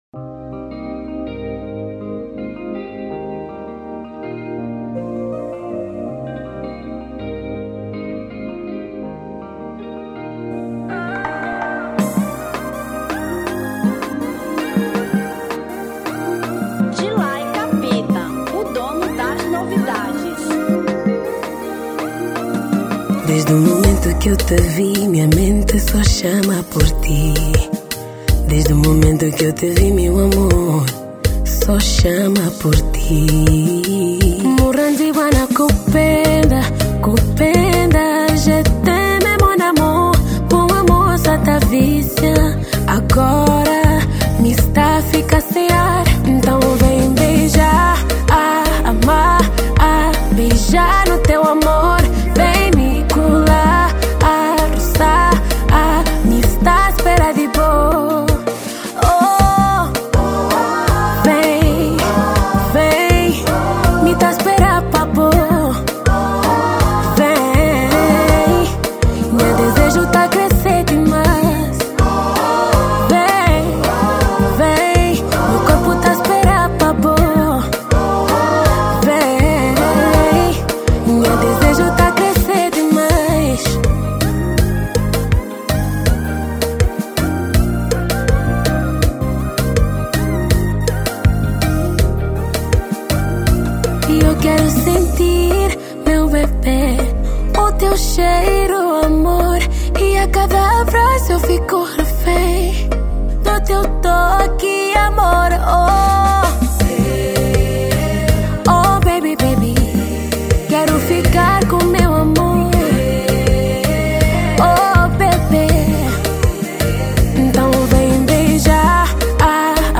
Kizomba 2025